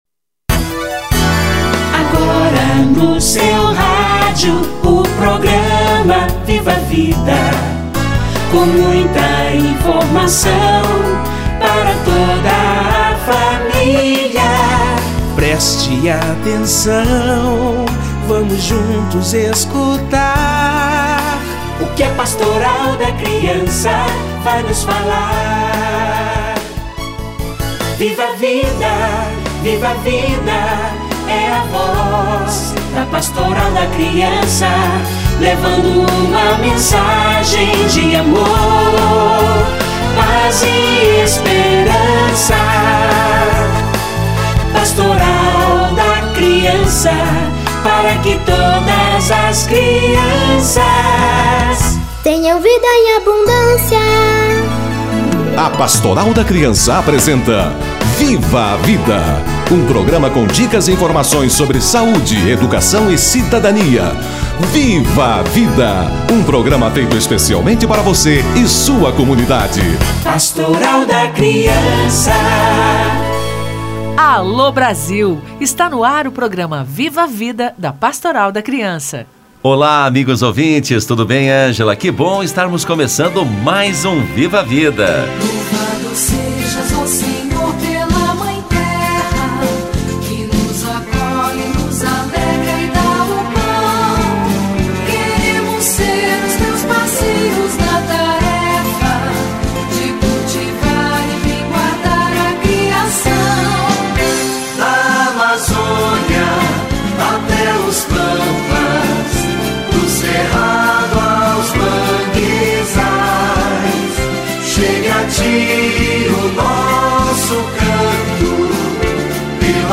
Campanha da Fraternidade 2017 - Entrevista